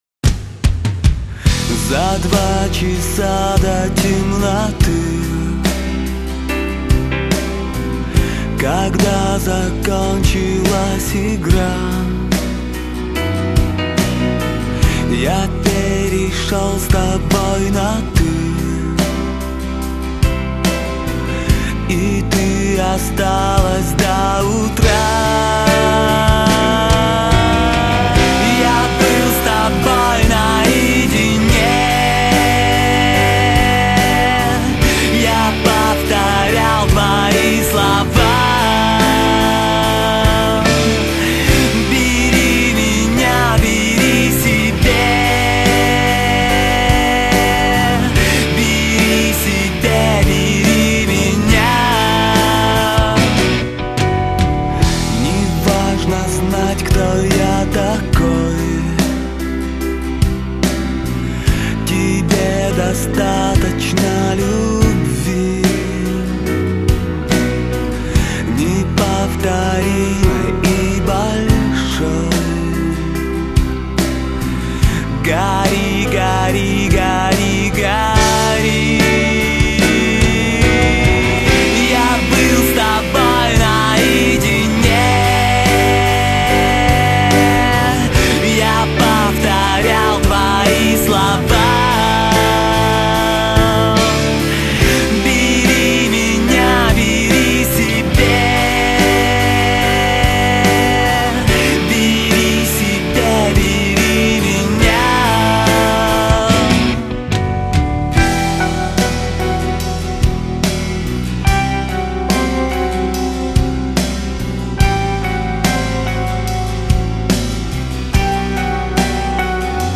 Русский рок Поп Рок